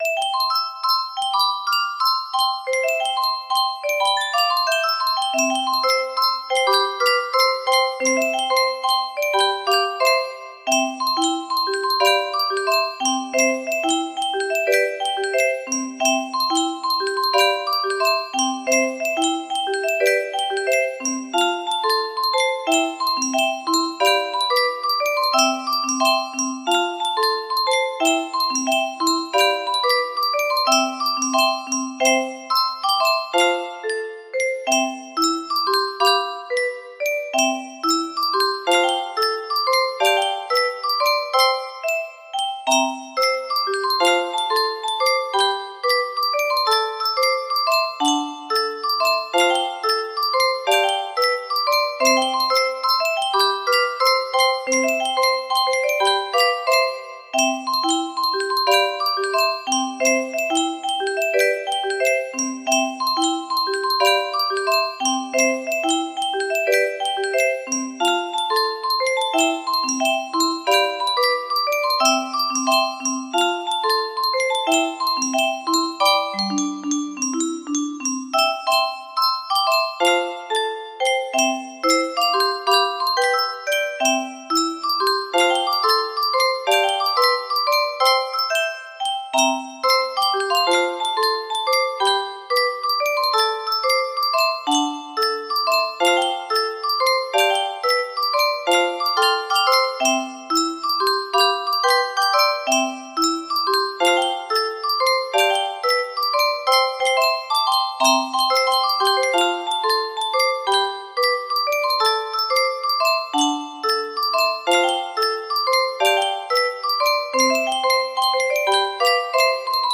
30 (F scale)